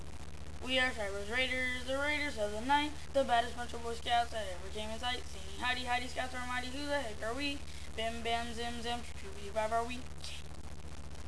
Click Schreiber's Raiders to hear them sing.